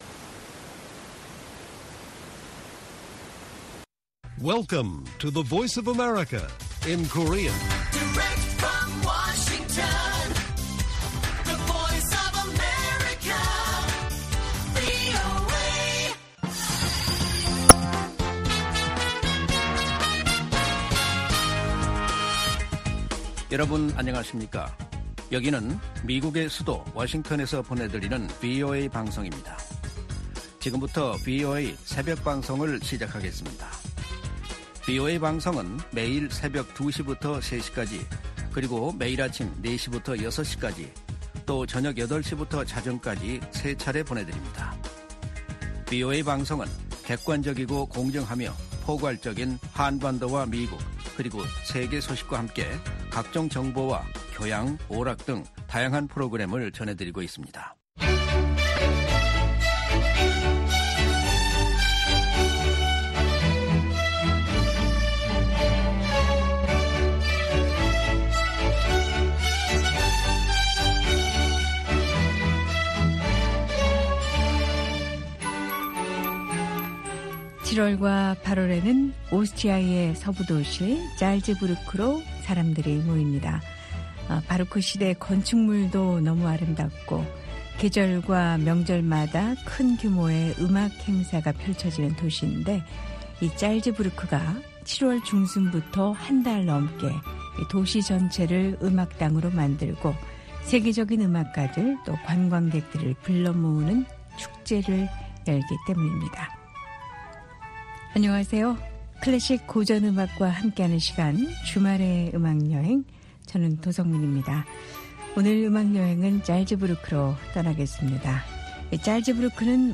VOA 한국어 방송의 일요일 새벽 방송입니다. 한반도 시간 오전 2:00 부터 3:00 까지 방송됩니다.